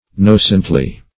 nocently - definition of nocently - synonyms, pronunciation, spelling from Free Dictionary Search Result for " nocently" : The Collaborative International Dictionary of English v.0.48: Nocently \No"cent*ly\, adv.
nocently.mp3